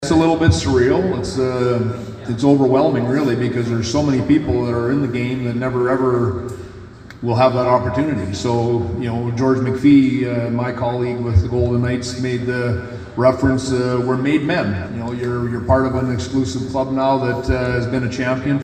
The Stanley Cup made a stop just south of Saskatoon Thursday at the Glen At Crossmount Event and Wedding Venue.